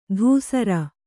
♪ dhūsara